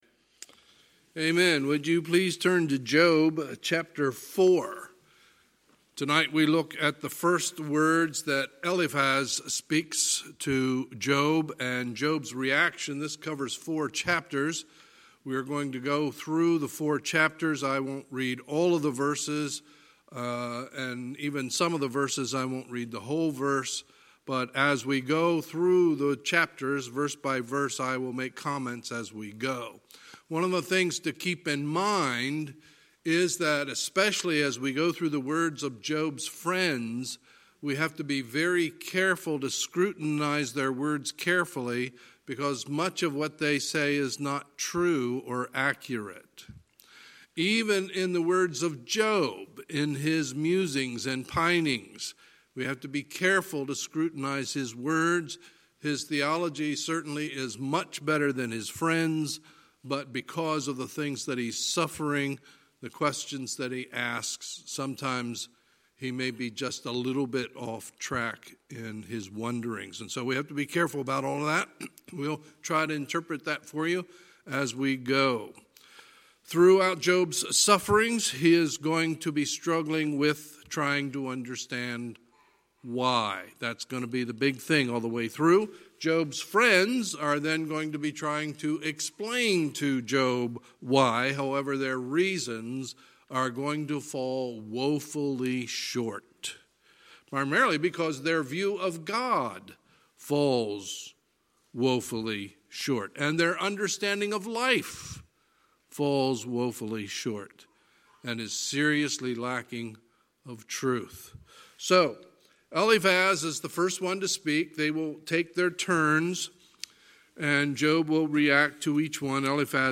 Sunday, January 26, 2020 – Sunday Evening Service
Sermons